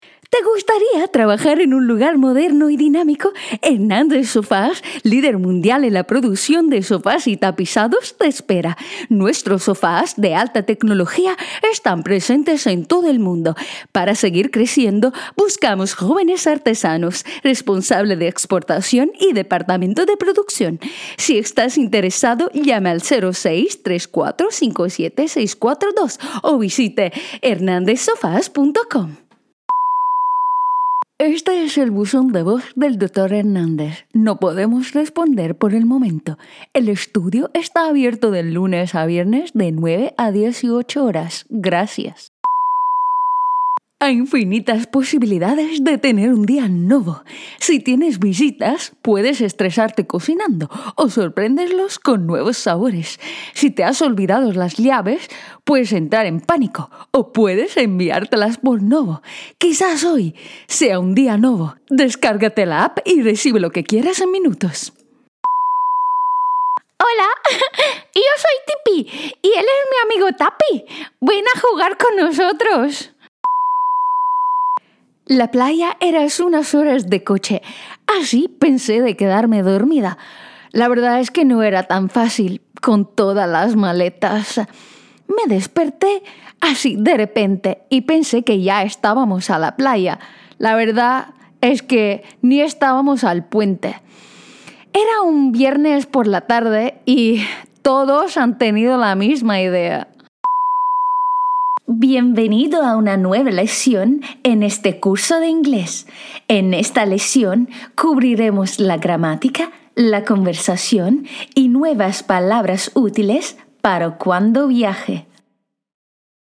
Young, Engaging, Deep, Cartoon, Narrator
Spanish Demo
Spanish - Neutral
Young Adult